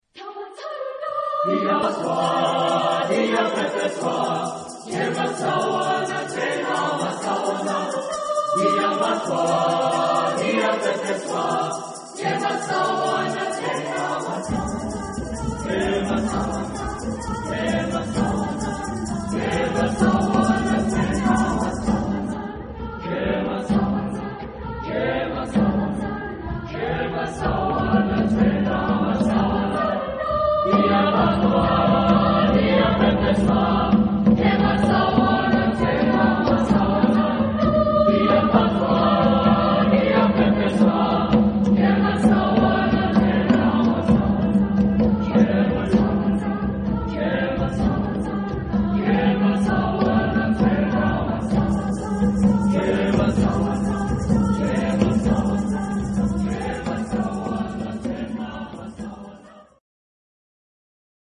Genre-Style-Forme : Traditionnel ; Folklore
Caractère de la pièce : vivant ; satirique
Type de choeur : SATB
Tonalité : sol majeur
interprété par Drakensberg Boys' Choir